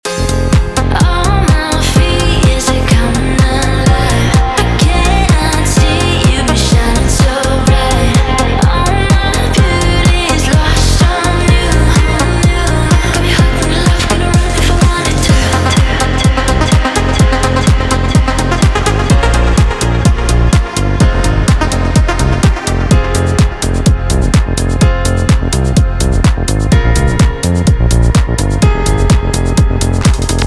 Drums, bass, chords, pads, and more - in seconds.